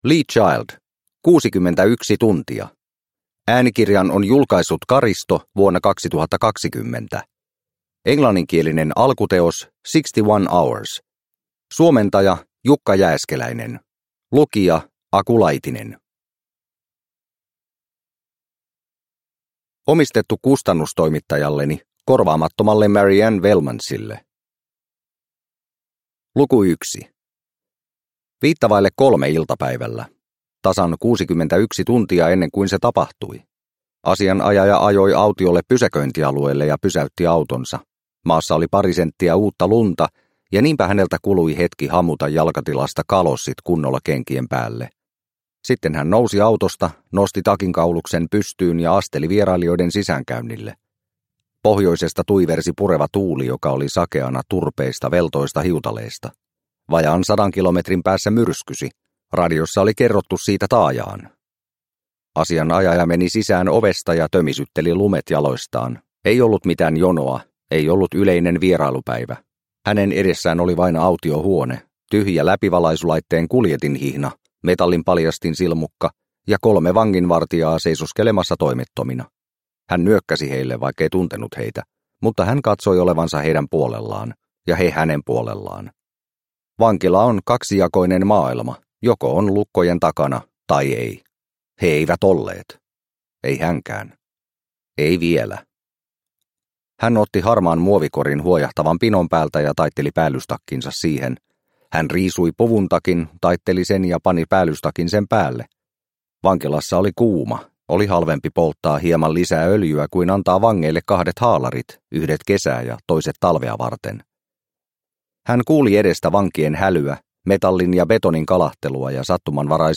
61 tuntia – Ljudbok – Laddas ner